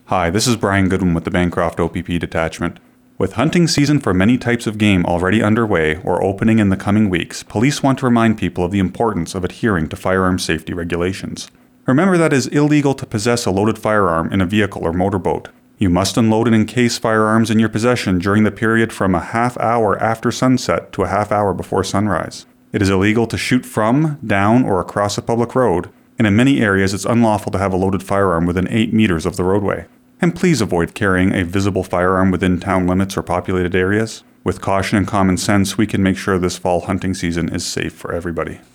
OPP releases Public Service Announcement for hunting season
OPP-hunting-PSA.wav